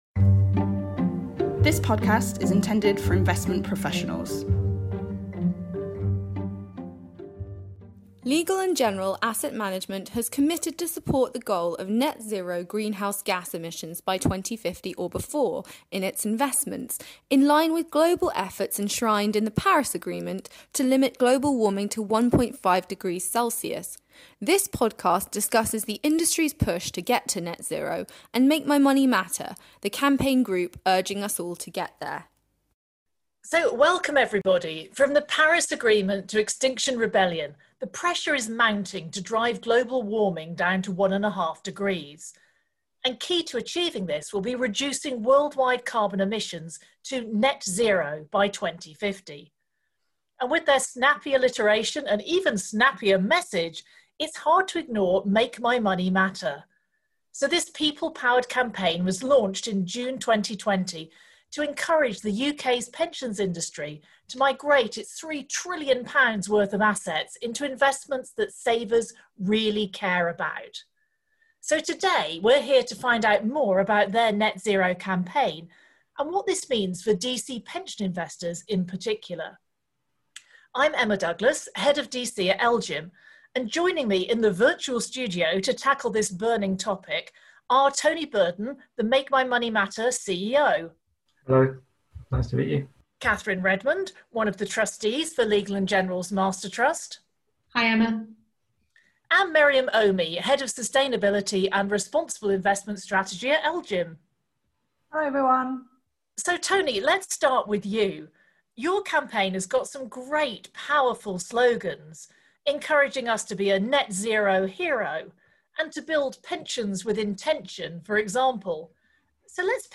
a panel of DC and climate experts